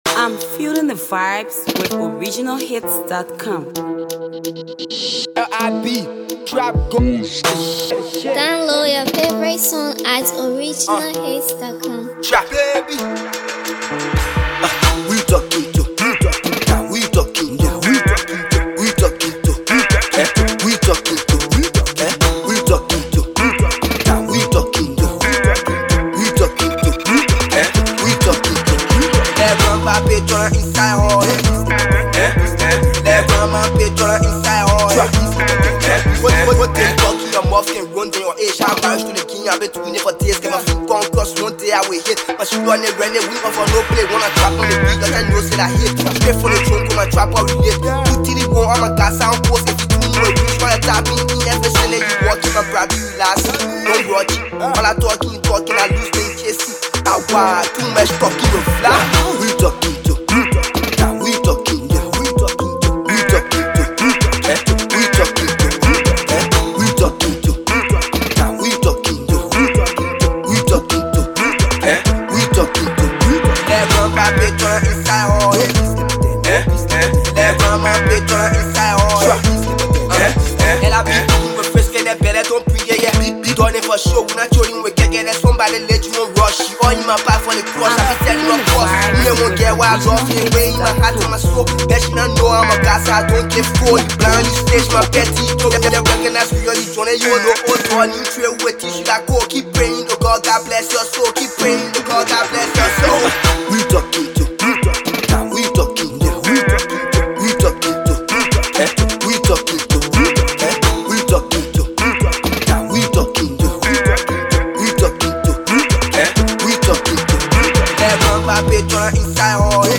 Liberian uprising trap artist